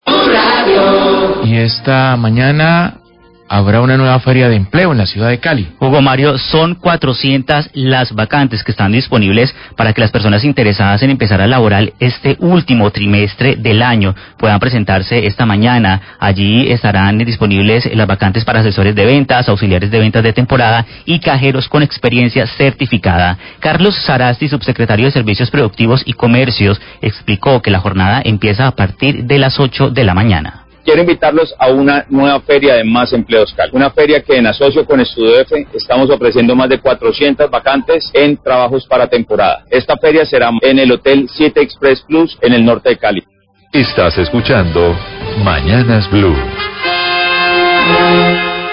Radio
La Secretaría de Desarrollo Económico realiza una nueva versión de la feria de empleo "Más Empleos Cali" que ofrecerá 400 vacantes. El Subsecretario de Servicios Productivos y Comercio, Carlos Sarasti, invita a esta feria.